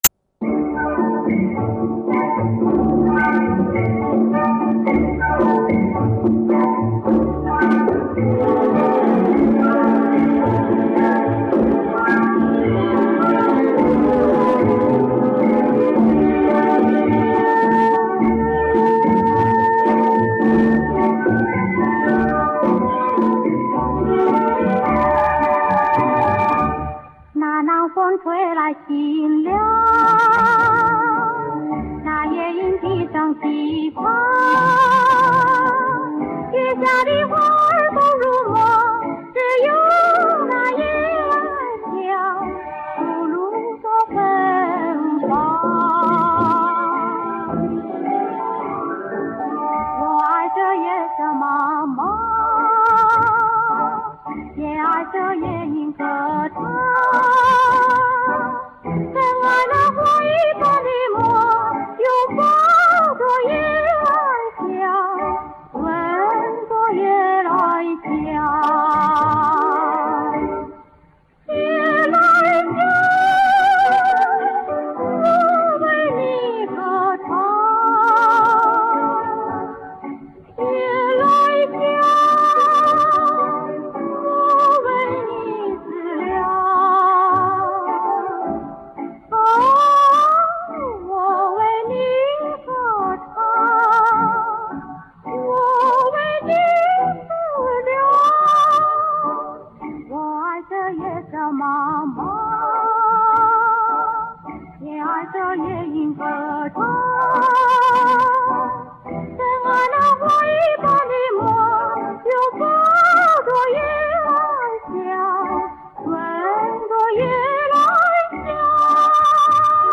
应考虑到歌曲都是几十年前的录音，调整一下自己的“金耳朵”，希望乐